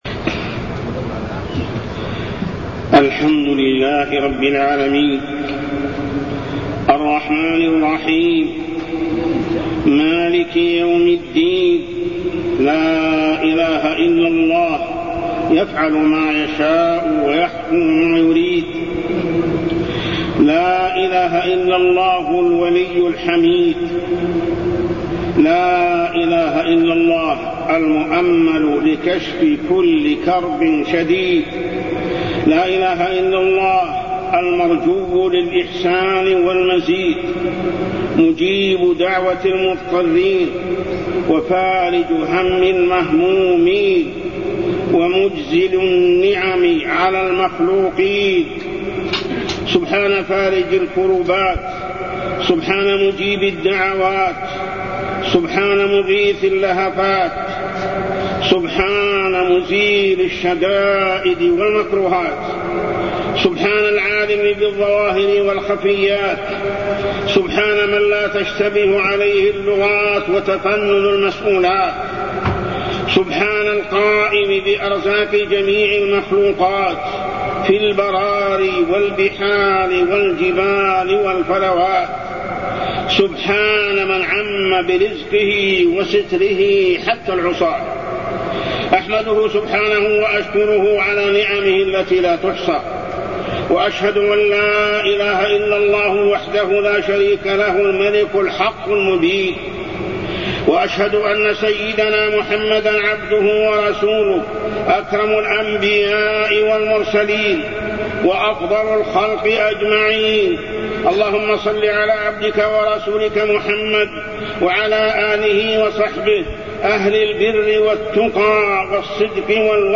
تاريخ النشر ٣ شعبان ١٤٢١ هـ المكان: المسجد الحرام الشيخ: محمد بن عبد الله السبيل محمد بن عبد الله السبيل المحافظة على الطاعات